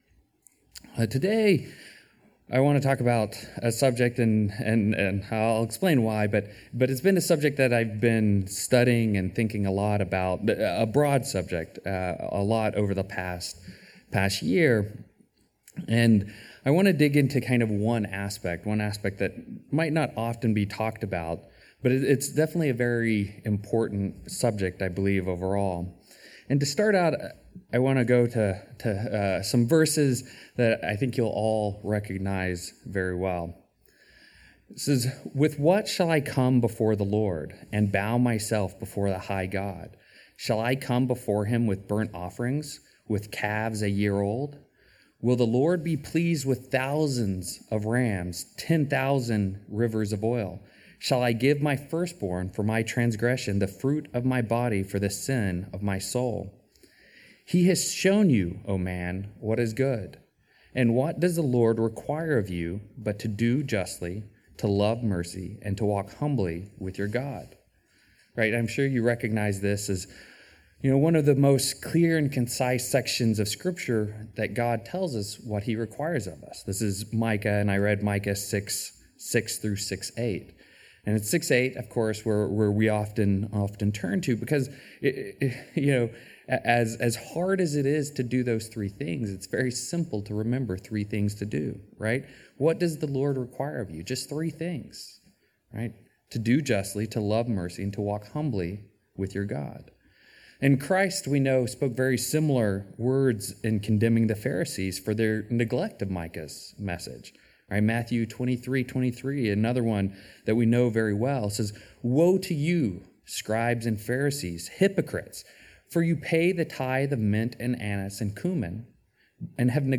Sermons
Given in Seattle, WA Burlington, WA